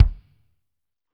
Index of /90_sSampleCDs/Northstar - Drumscapes Roland/KIK_Kicks/KIK_P_C Kicks x